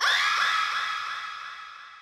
TM88 ScreamFX.wav